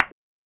key-press-1.wav